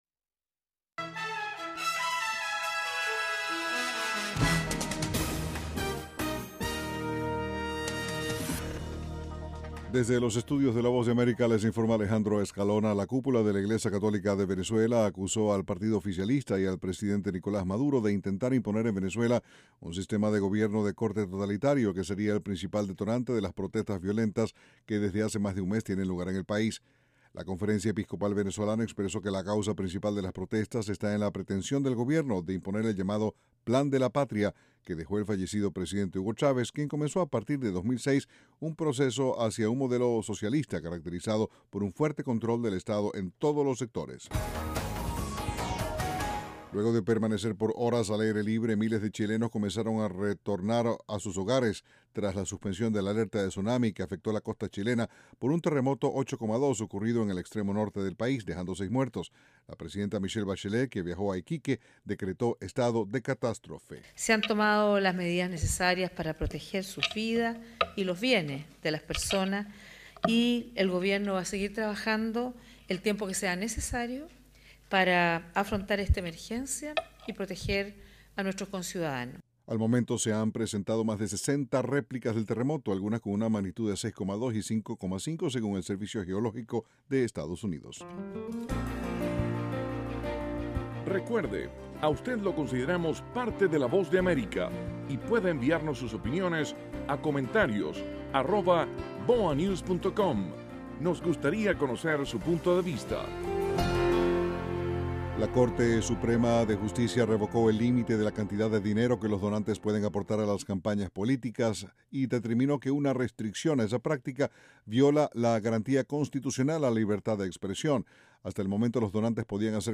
En cinco minutos de duración, Informativo VOASAT ofrece un servicio de noticias que se transmite vía satélite desde los estudios de la Voz de América.